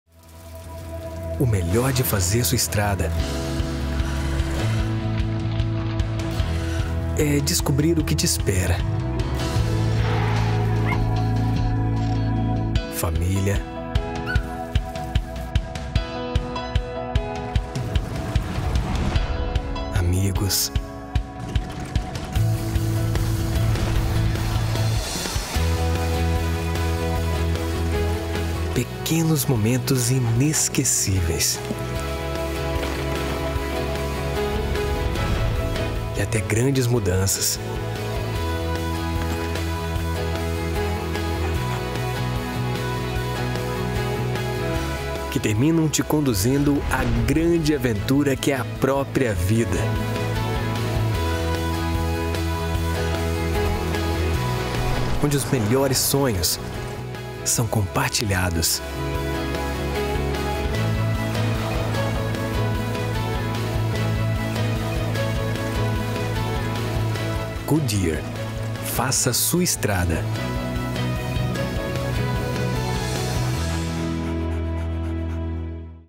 Male
I have the experience to create a versatile and natural interpretation just the way your project needs it with a youthful, friendly and kind voice.
Television Spots
Words that describe my voice are youthful, Conversational, friendly.